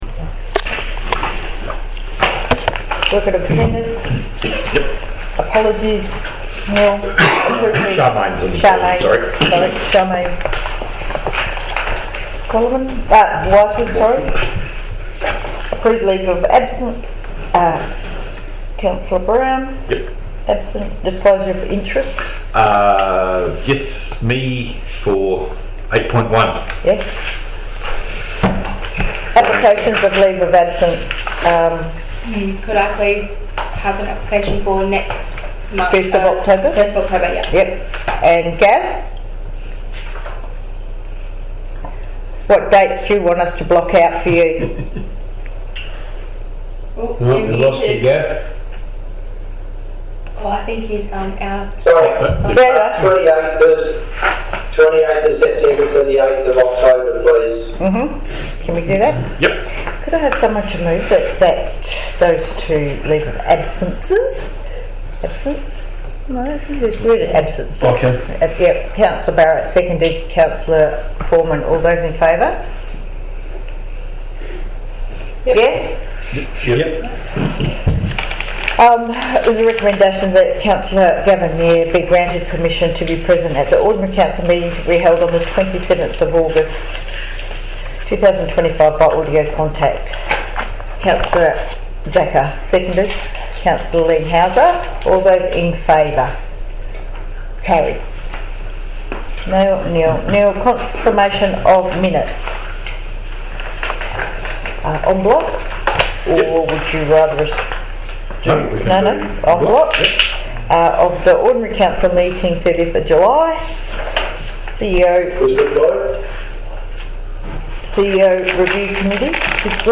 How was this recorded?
Ordinary Council Meeting - 27 August 2025 - Recording (15.76 MB)